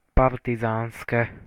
Partizánske (Slovak pronunciation: [ˈpartizaːnske]
Sk-Partizanske.ogg.mp3